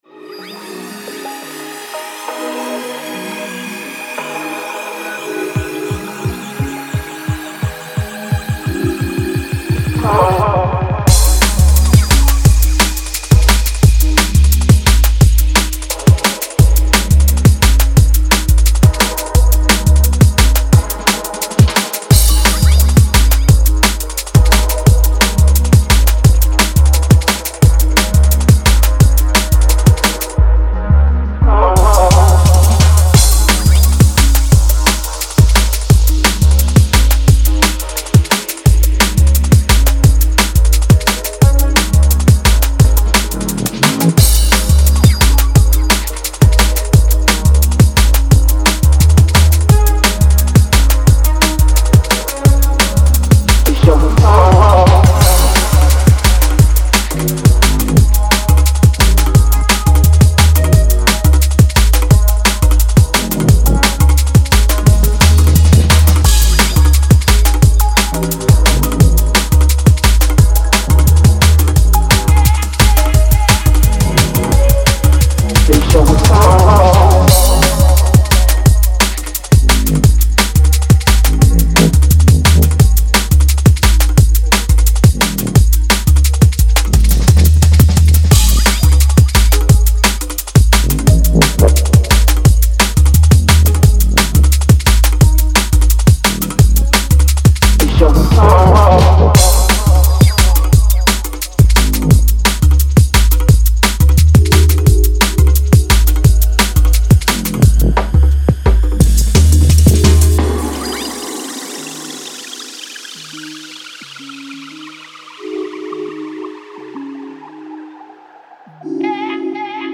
We’ve got two tracks absolutely drenched in soul and funk